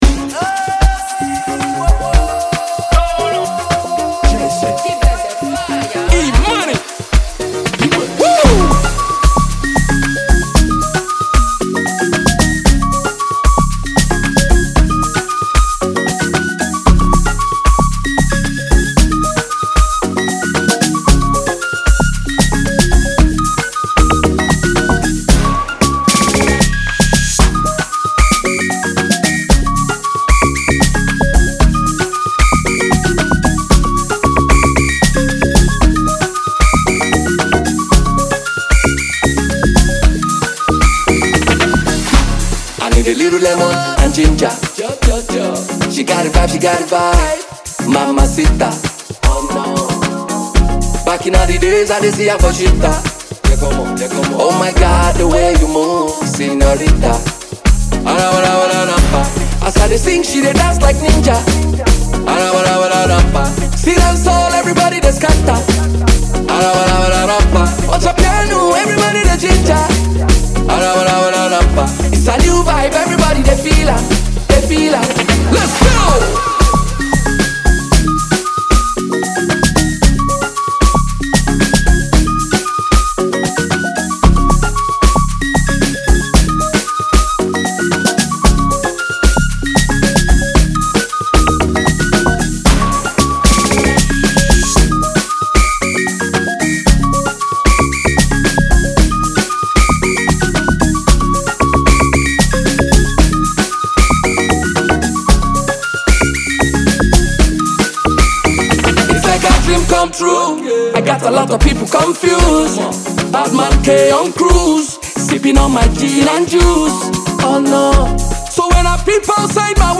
Nigerian Afrobeat and highlife singer